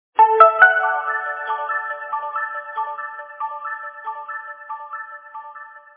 alarm5.mp3